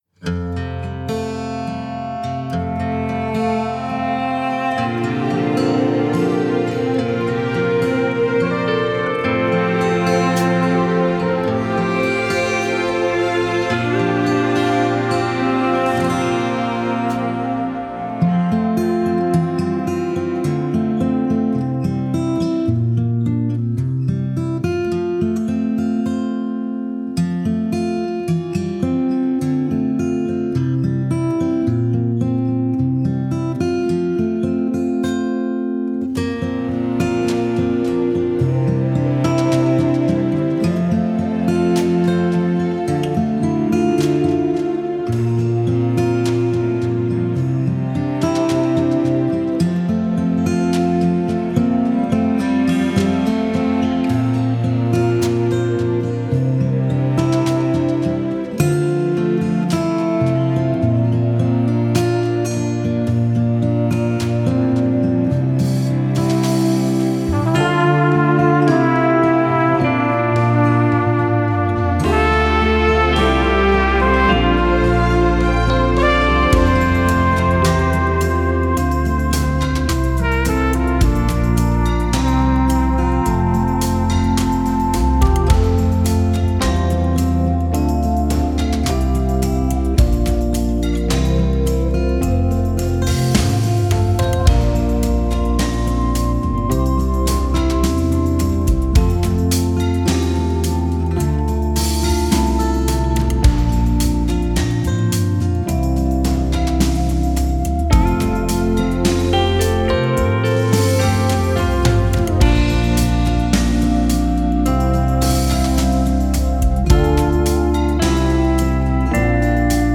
mp3 伴奏音樂